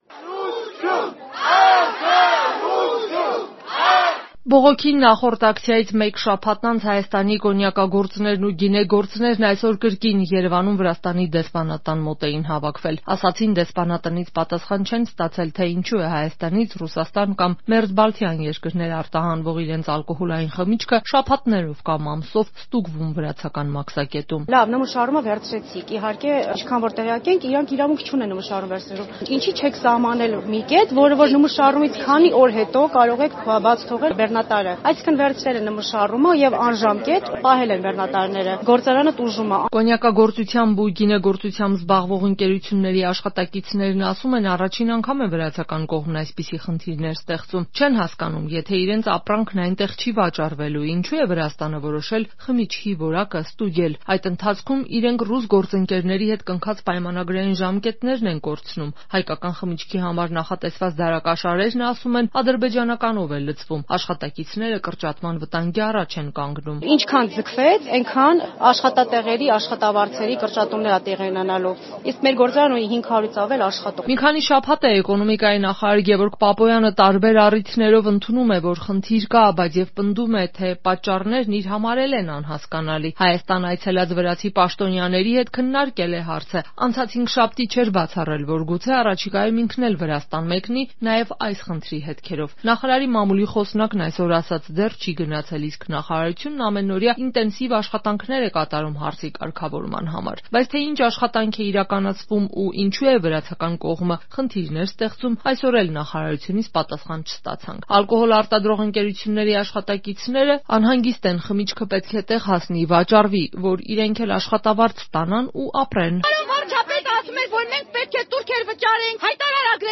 Ռեպորտաժներ
«Սա տնտեսական տեռոր է Հայաստանի կոնյակագործության հանդեպ». Բողոքի ակցիա Վրաստանի դեսպանատան մոտ